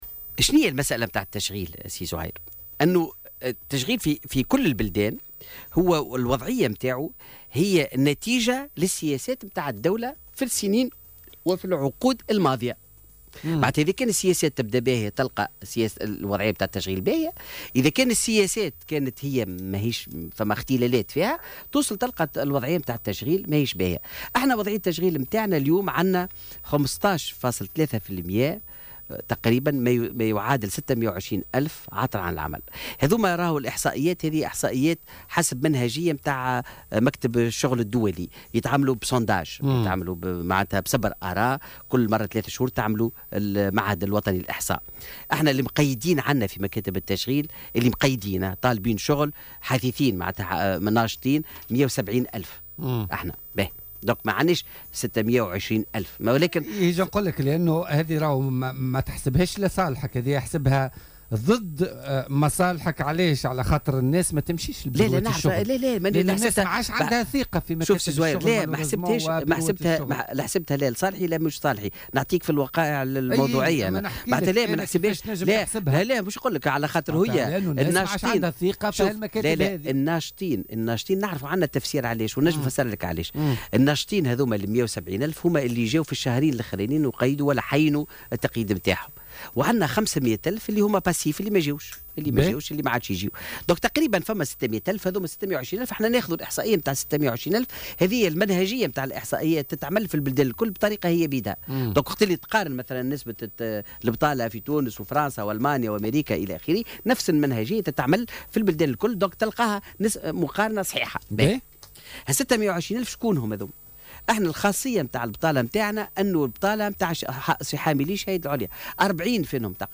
Le ministre de la Formation professionnelle et de l'Emploi, Faouzi Abderrahmane, est revenu jeudi, sur les ondes de Jawhara FM, sur les chiffres du chômage en Tunisie et les solutions proposées par le ministère.